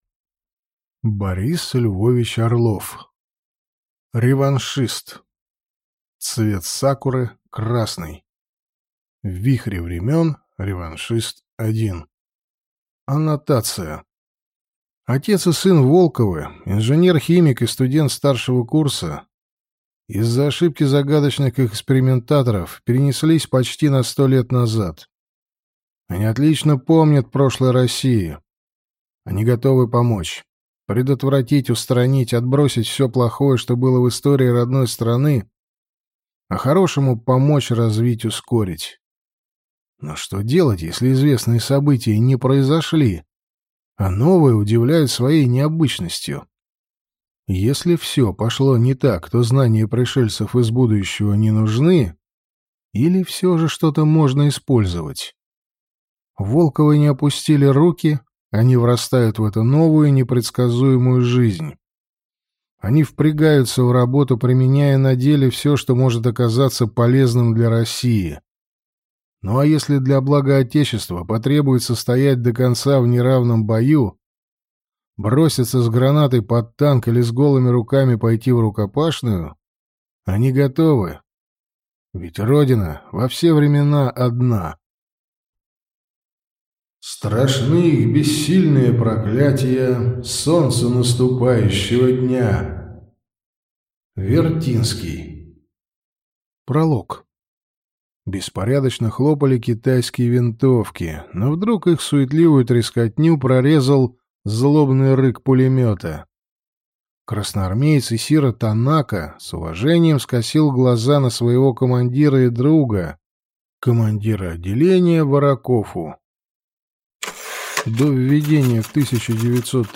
Аудиокнига Реваншист. Цвет сакуры – красный | Библиотека аудиокниг